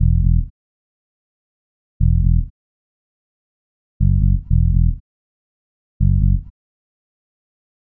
描述：心跳低沉的贝斯旋律，音符E到F。
Tag: 120 bpm House Loops Bass Loops 1.35 MB wav Key : Unknown